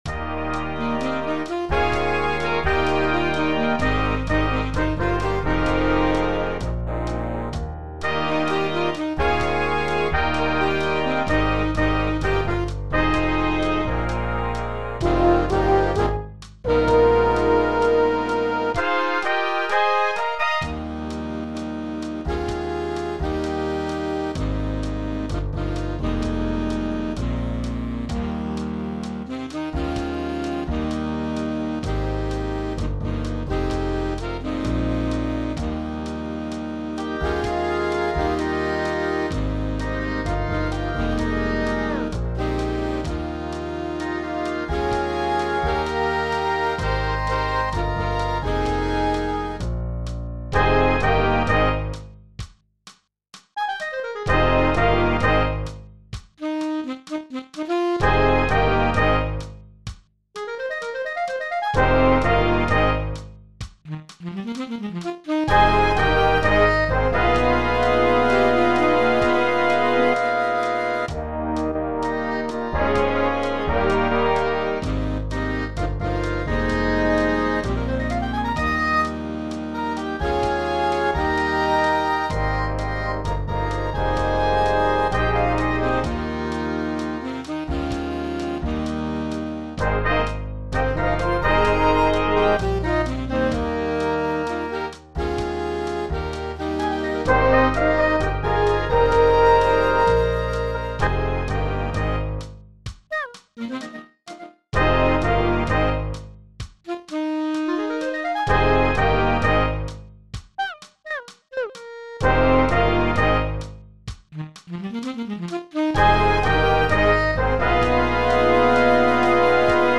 Fl�jt 1 Fl�jt 2 Altfl�jt Klarinett 1 Klarinett 2 Klarinett 3 Horn 1 Horn 2